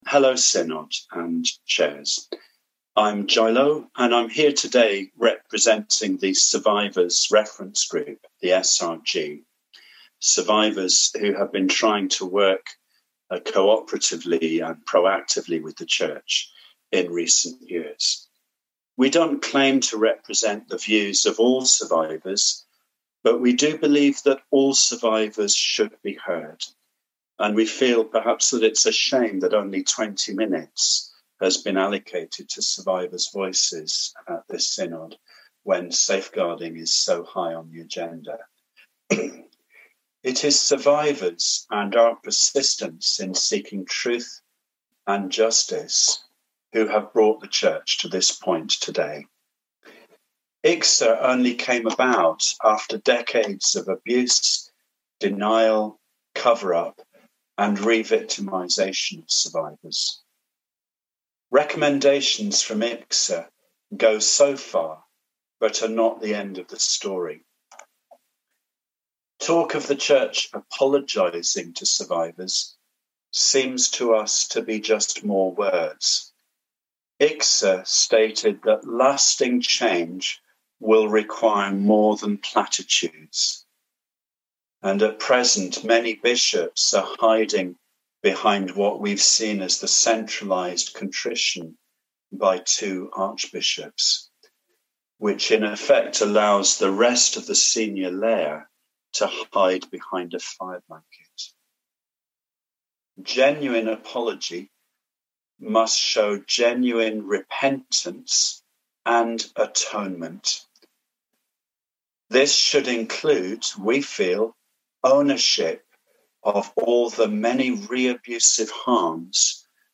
General Synod 25.11.20 - An extract from survivor contributions preceding the safeguarding debate on IICSA's recommendations to the Anglican Church.